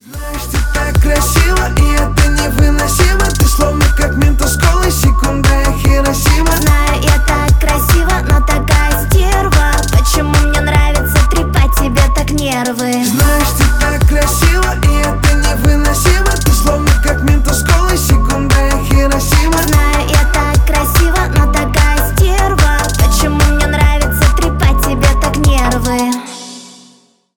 дуэт
поп